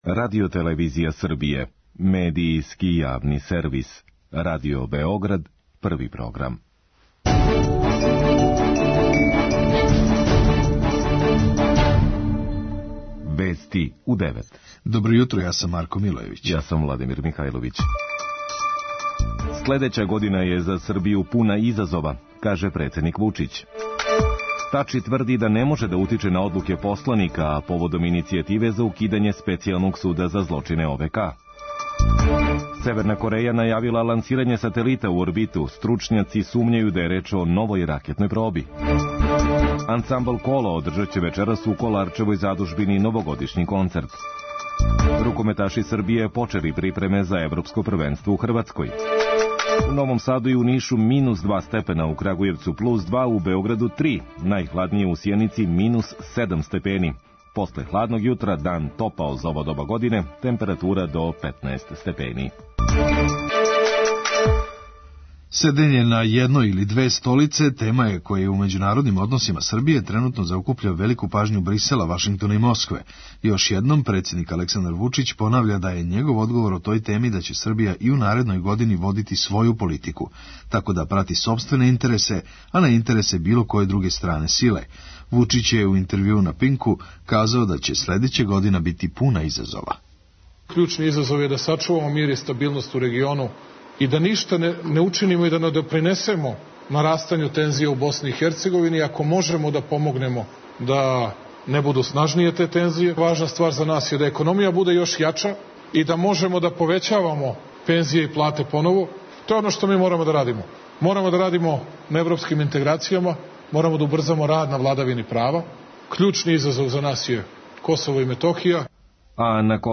преузми : 3.45 MB Вести у 9 Autor: разни аутори Преглед најважнијиx информација из земље из света.